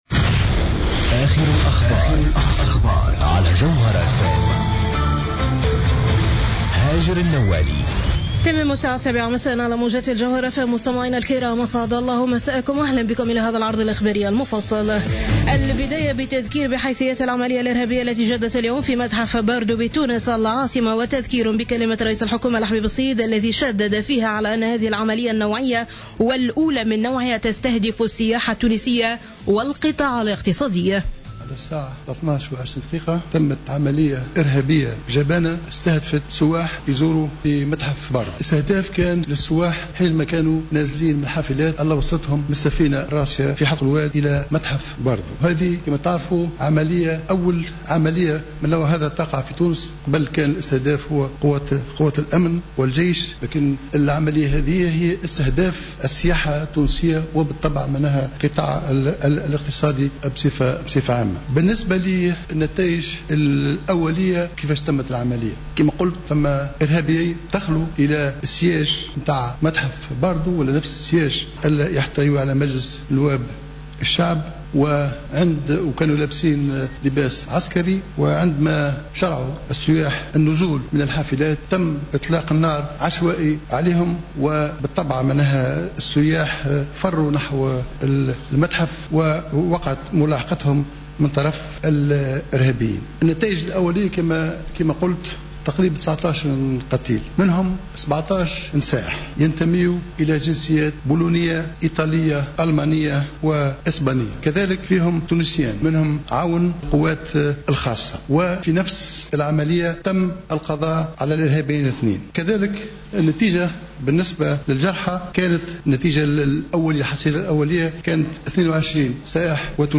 نشرة أخبار السابعة مساء ليوم الاربعاء 18 مارس 2015